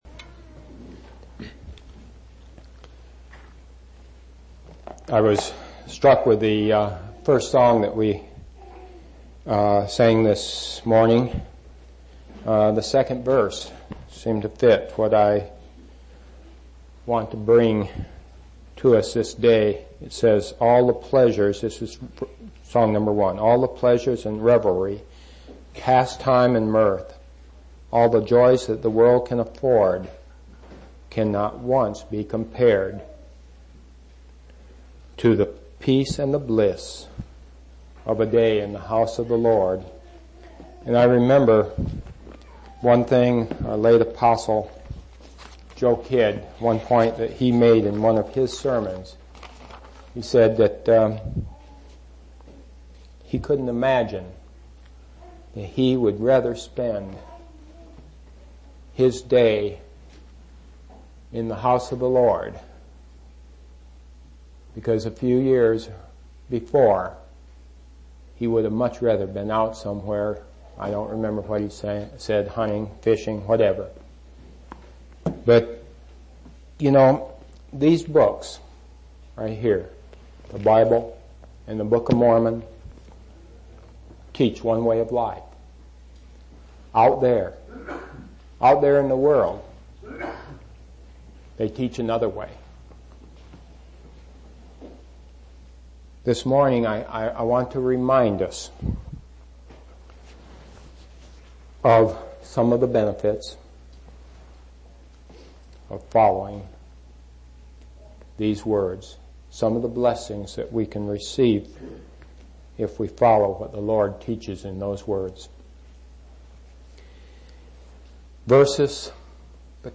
6/23/1991 Location: East Independence Local Event